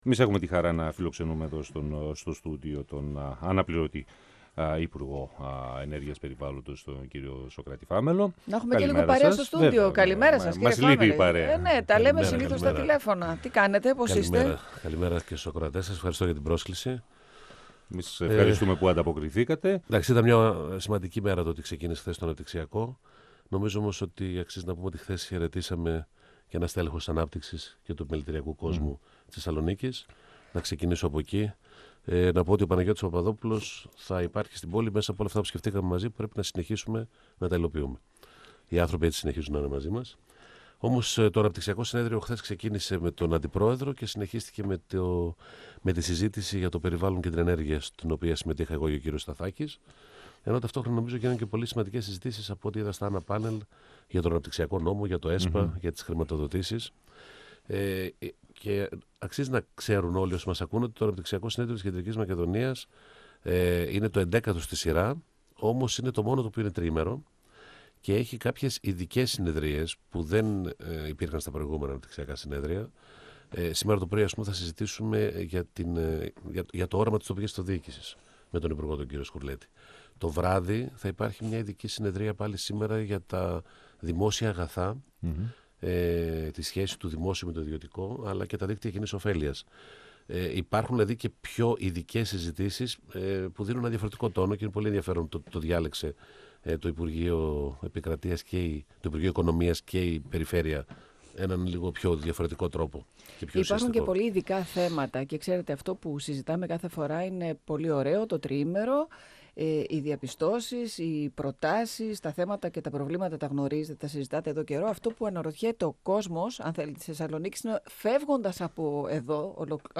Το 11ο αναπτυξιακό περιφερειακό συνέδριο Κεντρικής Μακεδονίας είναι σε εξέλιξη στη Θεσσαλονίκη.
Συνέντευξη